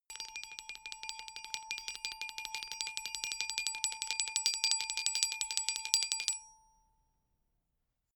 Royalty free music elements: Percussion